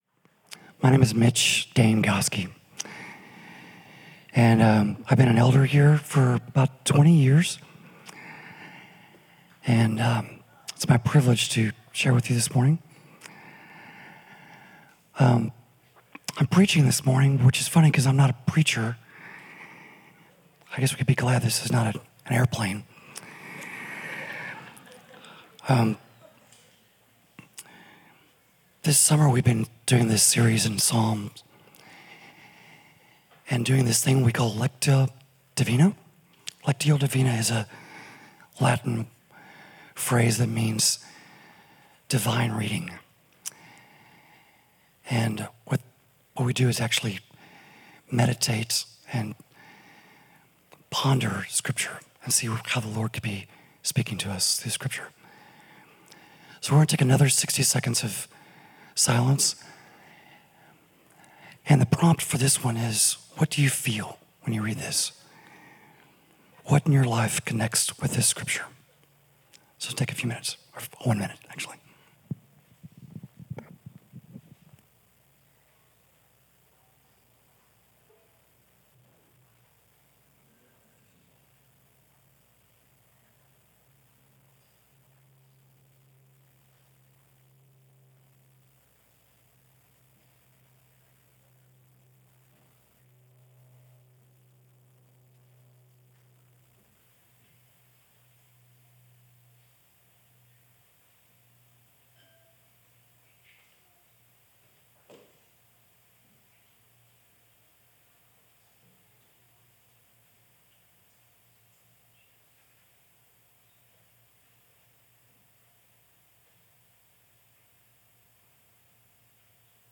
Midtown Fellowship Crieve Hall Sermons The Rod and The Staff Jul 07 2024 | 00:25:43 1x 00:00 / 00:25:43 Subscribe Share Apple Podcasts Spotify Overcast RSS Feed Share Link Embed